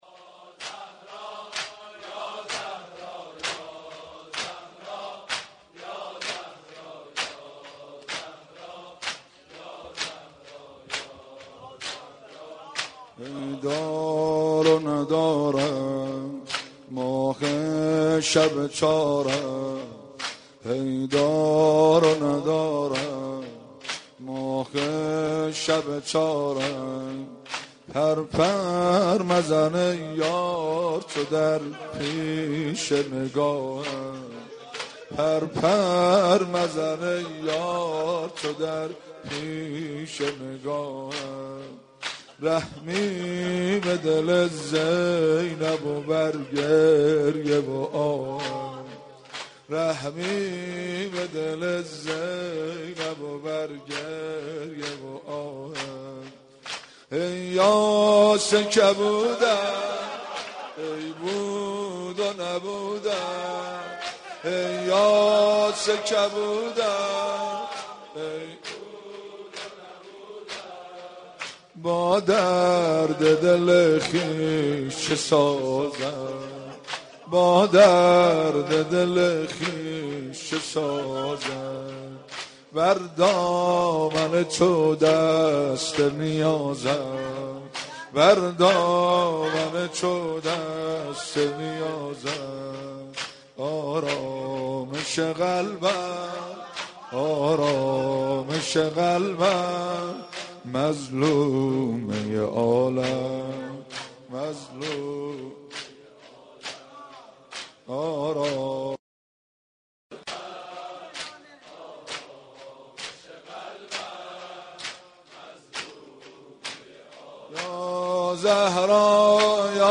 مداحی و نوحه
مراسم سینه زنی در شهادت یادگار پیامبر اکرم(ص